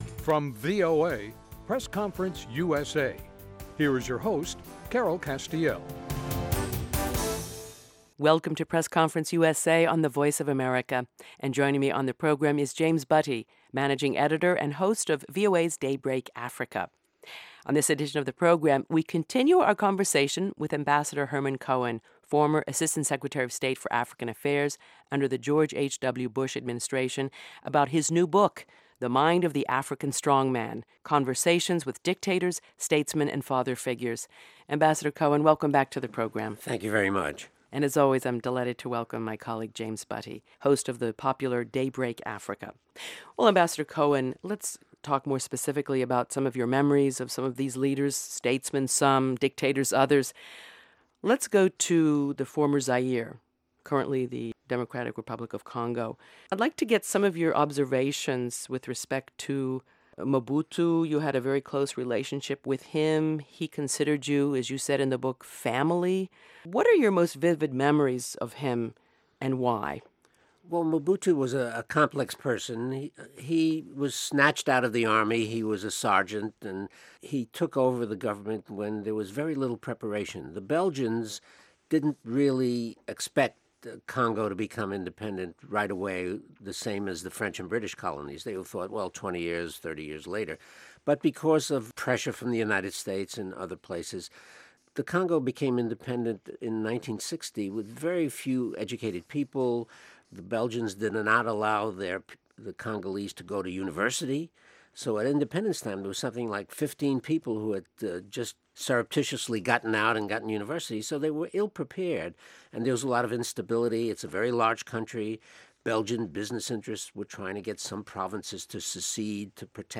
talk with Ambassador Herman (Hank) Cohen, former Assistant Secretary of State for African Affairs